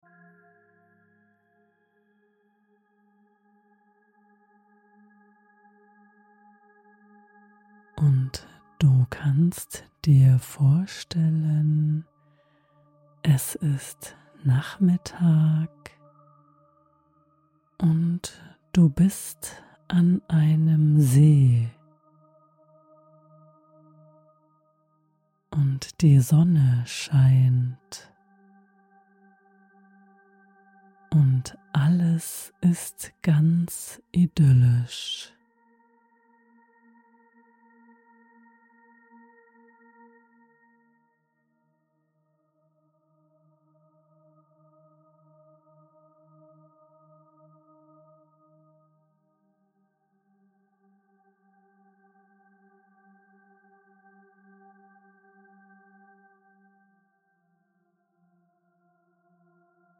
Fantasiereise Nachmittag am See
Die achtsame Sprache dieser Meditation unterstützt dich dabei, Körper und Geist in Einklang zu bringen, deine Sinne sanft zu öffnen und ganz in dir anzukommen.